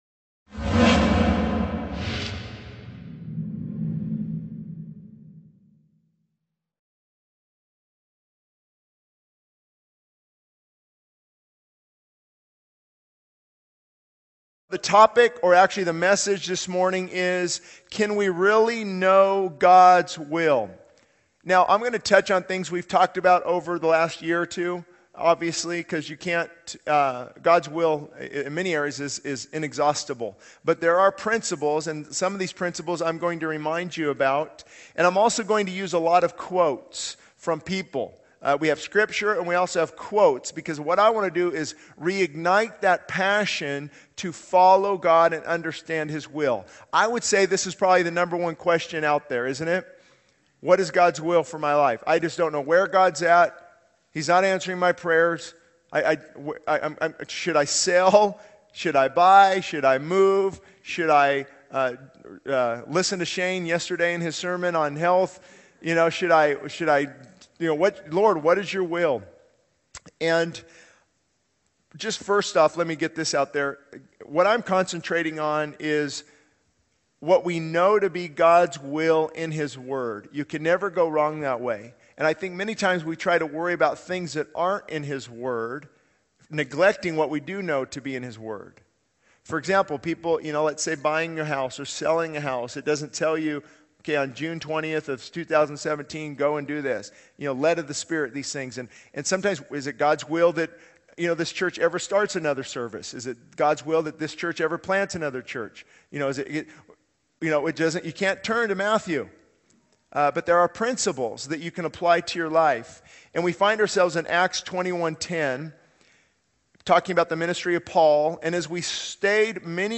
This sermon delves into the topic of understanding God's will, emphasizing the importance of focusing on what is revealed in His word. It highlights the principles of righteousness, self-control, and the impending judgment to come. The speaker encourages waiting in God's will, dealing with besetting sin, and the need for repentance and trust in Christ for salvation.